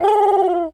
pigeon_call_angry_09.wav